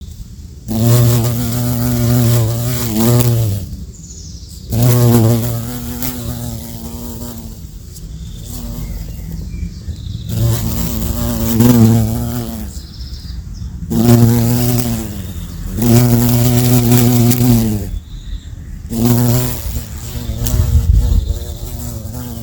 Blauschwarze_Holzbiene-.mp3